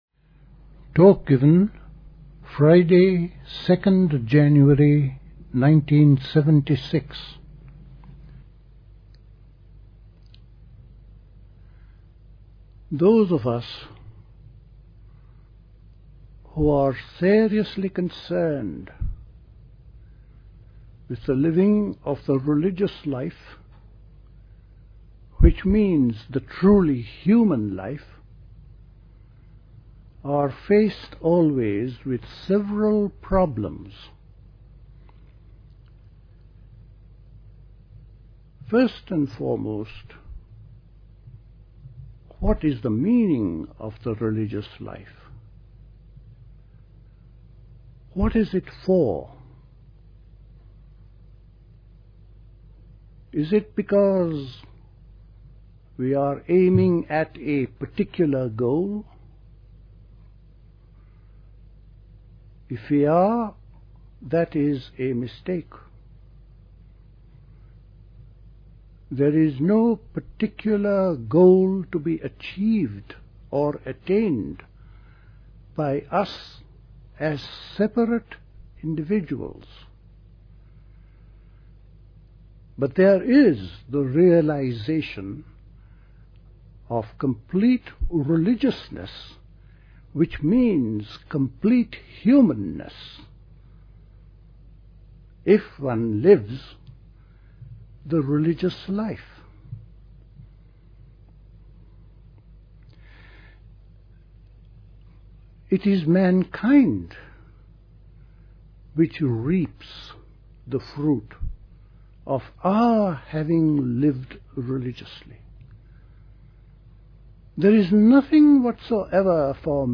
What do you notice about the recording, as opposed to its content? at Dilkusha, Forest Hill, London on 2nd January 1976